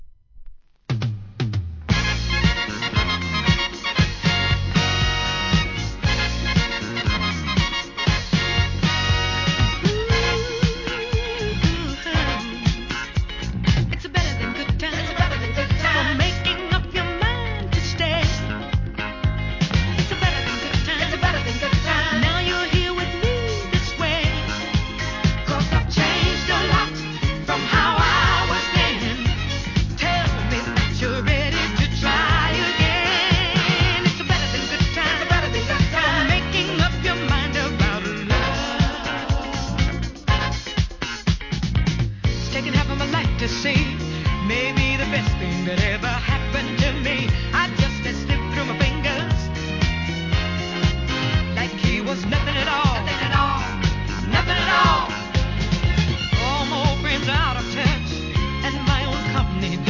SOUL/FUNK/etc... 店舗 ただいま品切れ中です お気に入りに追加 大ヒットのDISCOクラシック！！